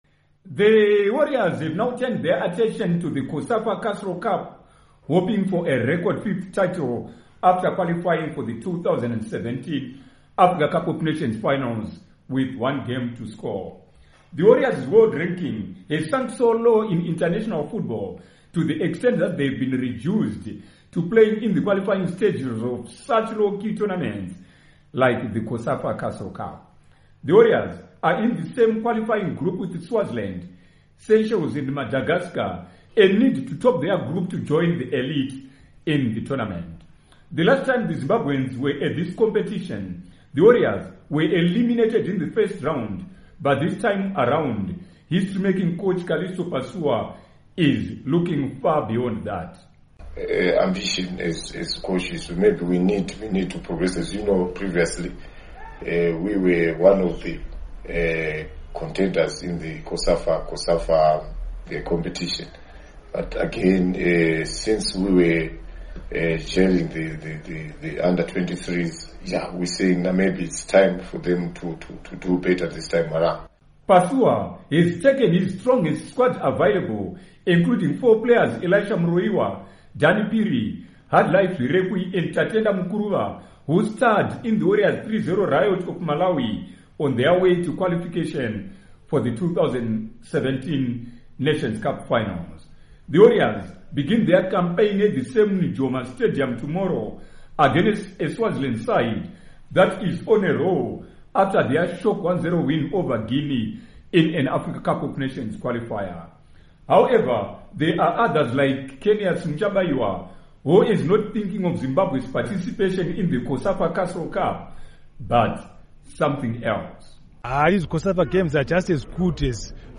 Report on Warriors Next Assignment